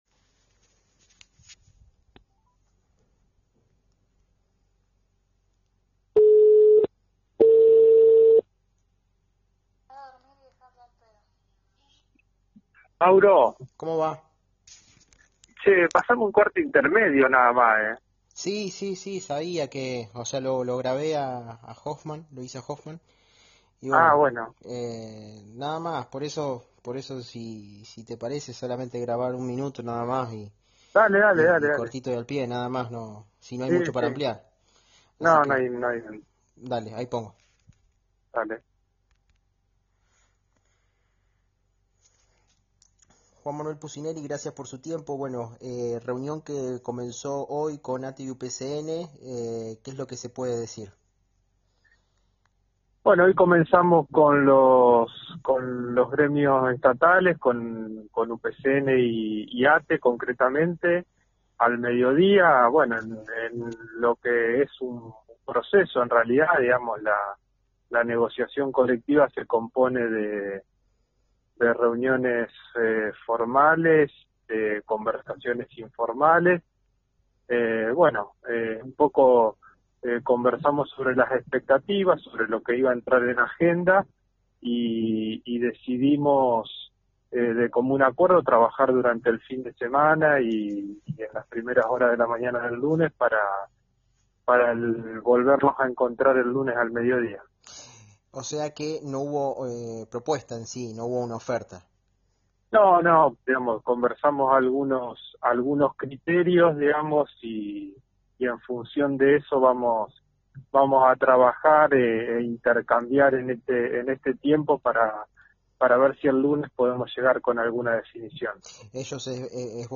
En dialogo con Radio EME, el secretario de Trabajo de la provincia de Santa Fe, Juan Manuel Pusineri, detalló: «Conversamos algunos criterios, y en función a eso trabajaremos durante el fin de semana para que el lunes podamos llegar a una definición».